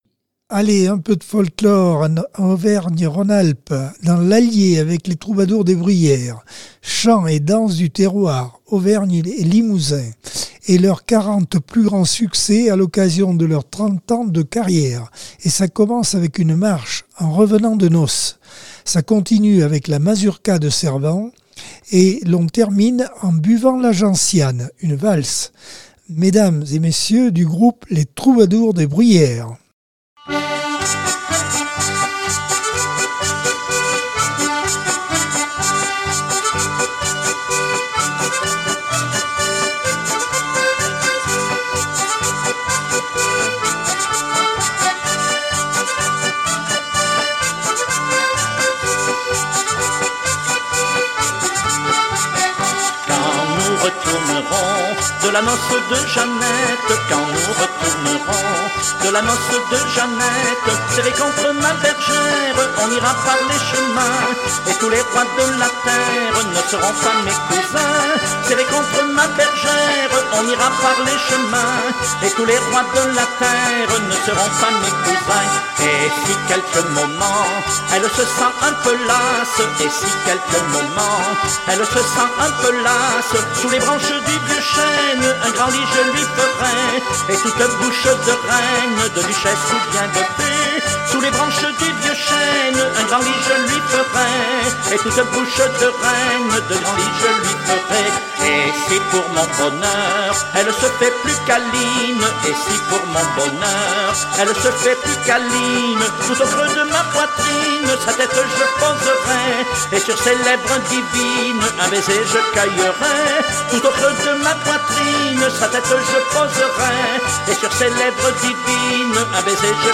Accordeon 2024 sem 14 bloc 2 - Radio ACX